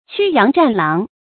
發音讀音
成語拼音 qū yáng zhàn láng